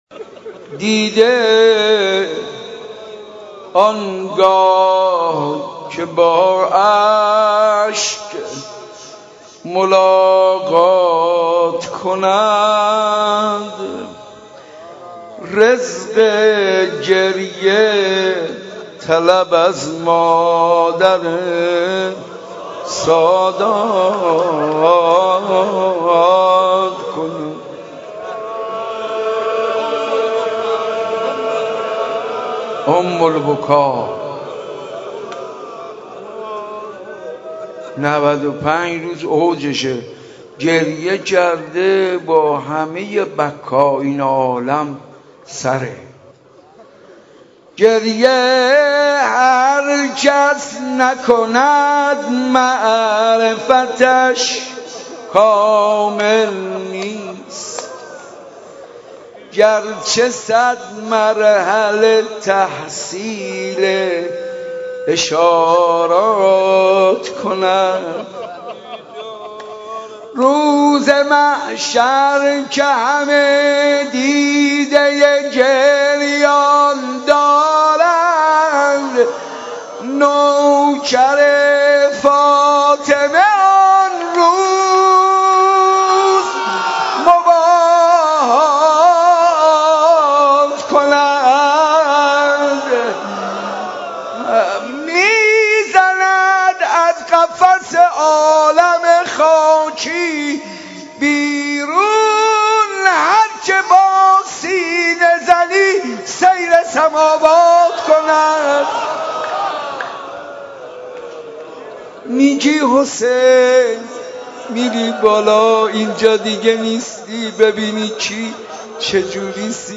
شب سوم فاطمیه 91 مسجد ارک